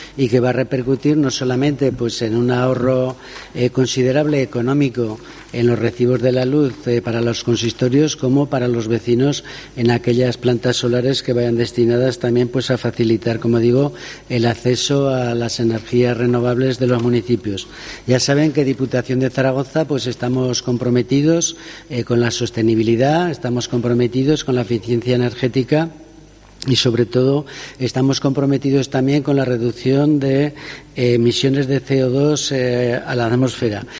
El presidente de la DPZ Sánchez Quero, explica el objetivo del plan de autoconsumo municipal